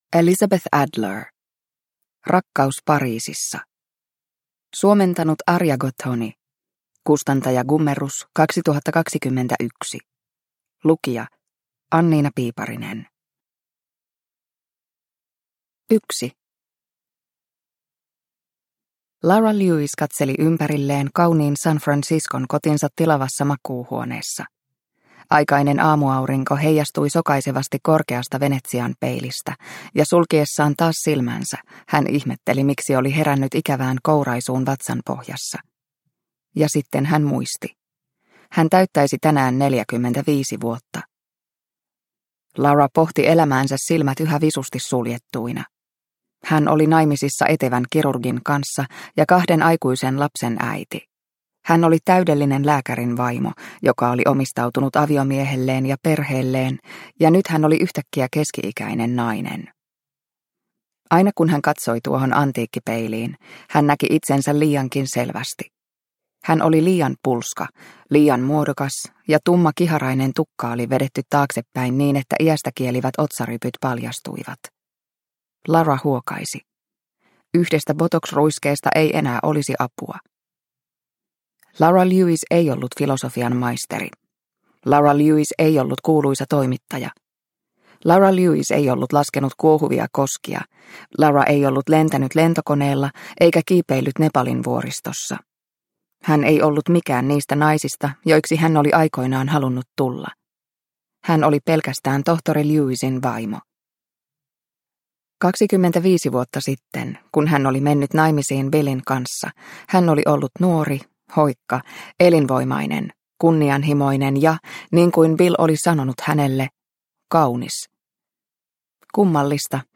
Rakkaus Pariisissa – Ljudbok – Laddas ner